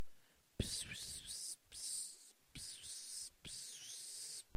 Whispering